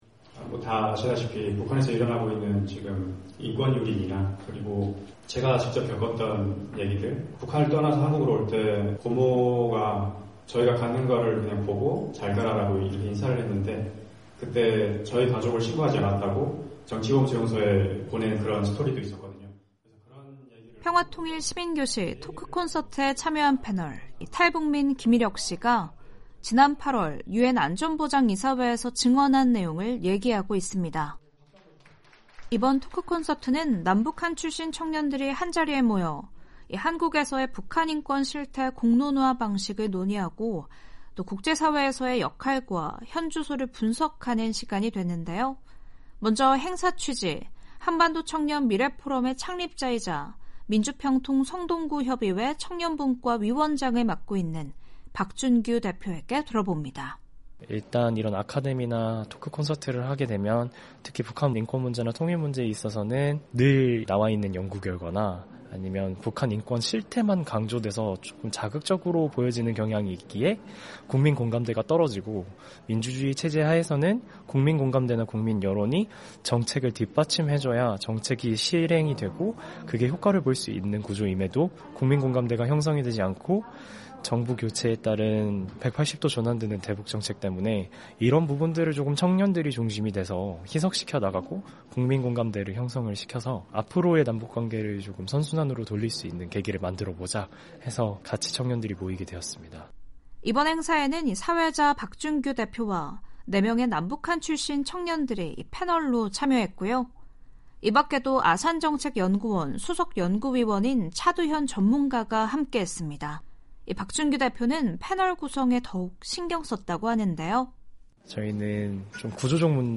북한 인권 개선을 위한 청년들의 새로운 해결책을 제시하는 청년 토크콘서트가 열렸습니다. 한반도 분야에서 재직하고 활동하는 실무자 청년들이 함께 모여 북한 인권 실태 개선에 대해 논의하는 시간을 가졌는데요. 탈북민들의 다양한 삶의 이야기를 전해드리는 '탈북민의 세상 보기', 오늘은 민주평통 성동구 협의회와 한반도청년미래포럼이 함께 기획한 '평화통일 시민교실 토크콘서트' 현장으로 안내해 드립니다.